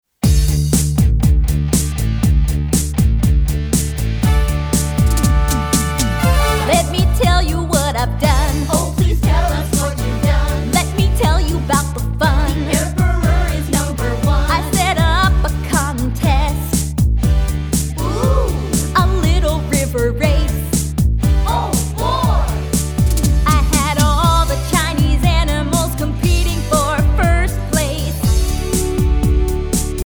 *  Catchy melodies, dumb jokes, interesting stories
song clip